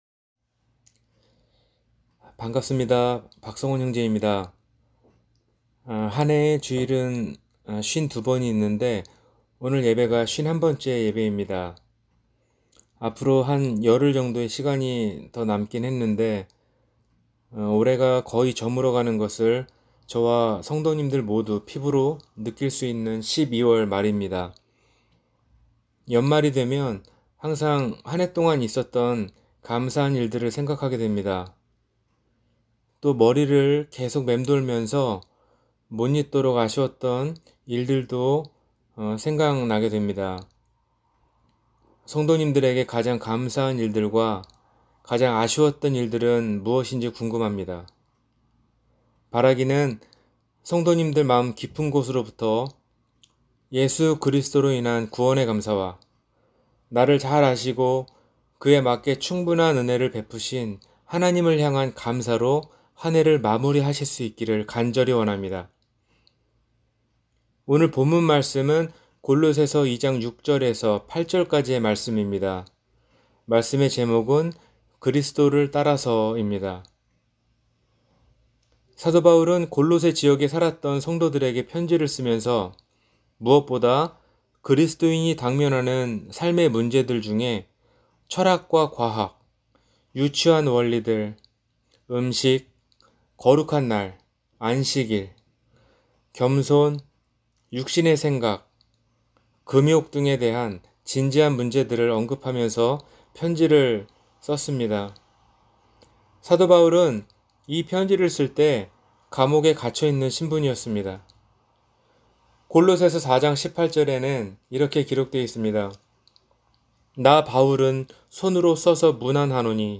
예수님을 따라서 – 주일설교